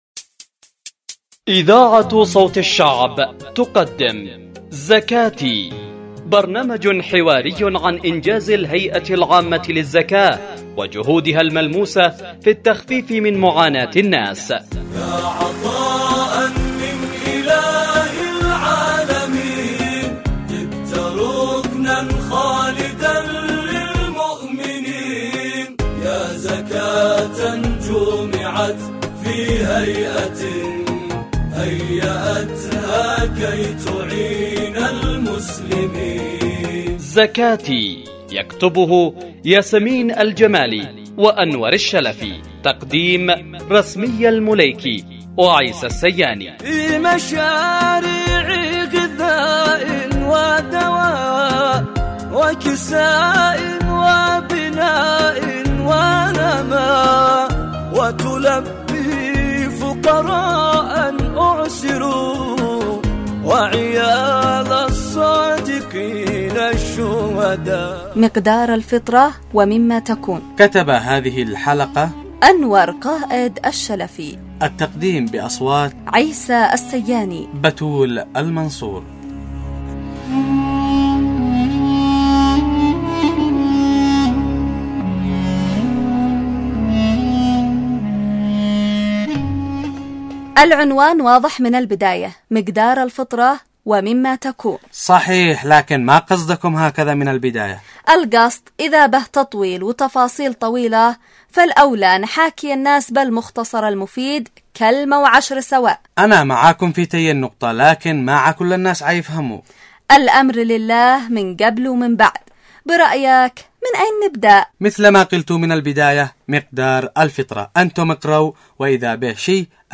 البرامج الحوارية